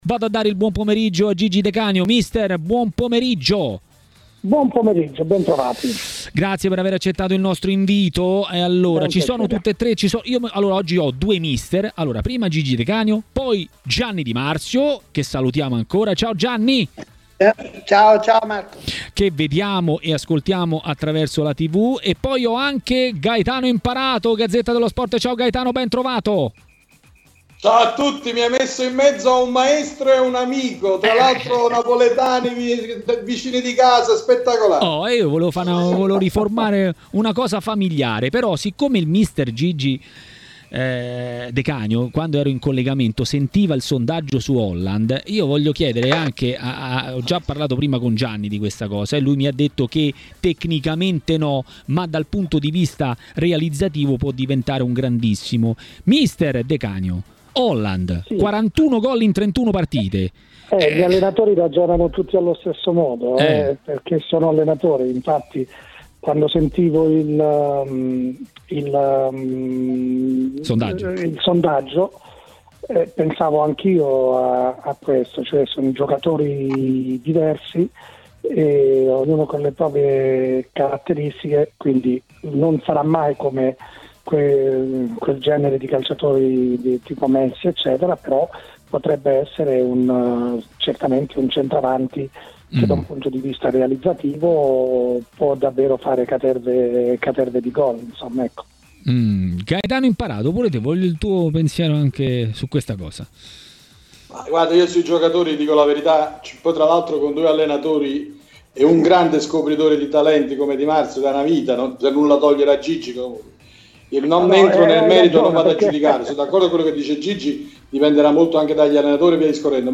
A parlare a TMW Radio, durante Maracanà, dei temi del momento è stato mister Luigi De Canio.